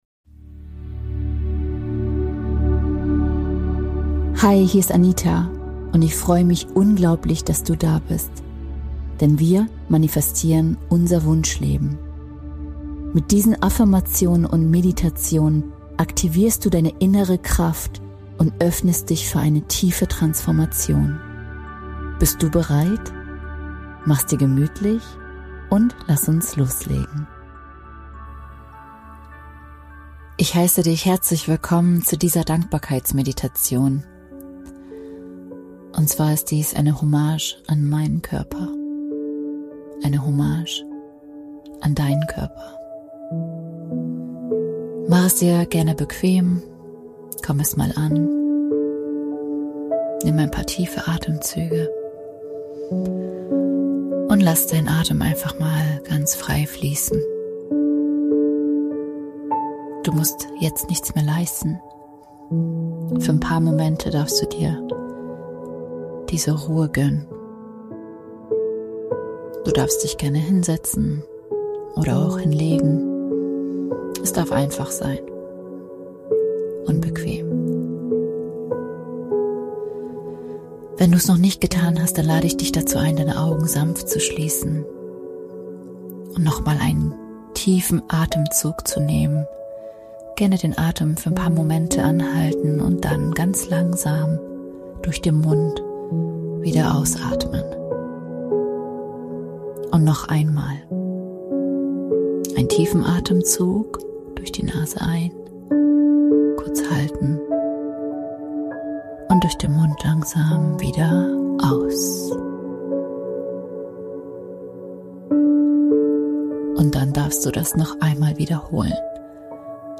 Beschreibung vor 3 Monaten In dieser geführten Dankbarkeitsmeditation richtest du deine Aufmerksamkeit liebevoll auf deinen Körper – auf all das, was er jeden Tag für dich tut, oft ganz unbemerkt. Sanft wirst du eingeladen, bewusst zu spüren, zu würdigen und Dankbarkeit zu kultivieren: für deinen Atem, dein Herz, deine Sinne, deine Organe und für deinen Körper als Ganzes.